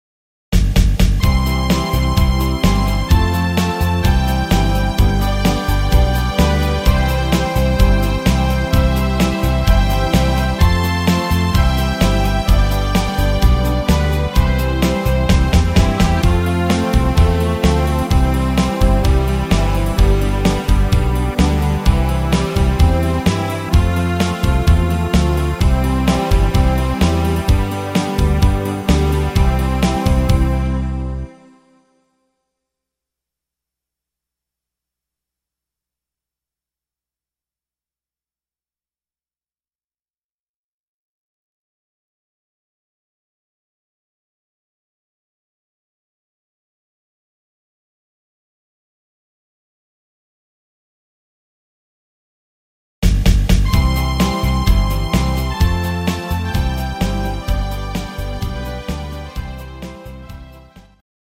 instr. Piano